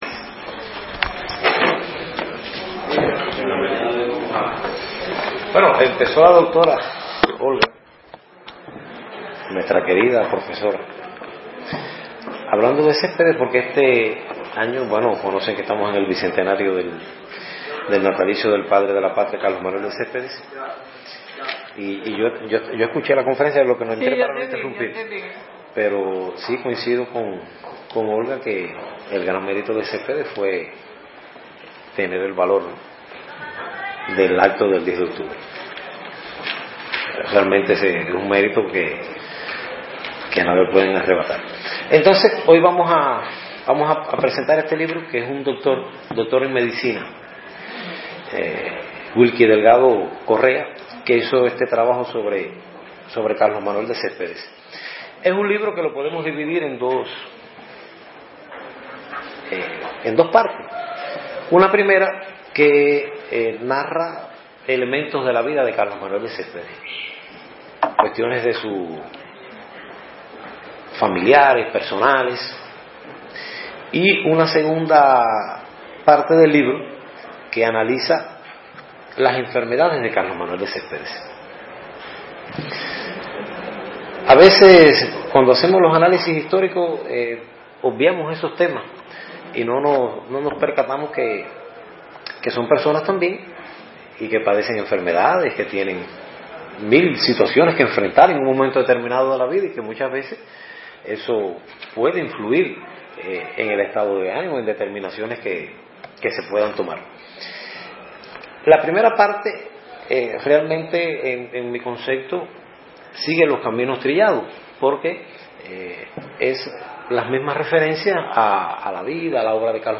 como parte del programa académico de la Feria del Libro en su edición granmense.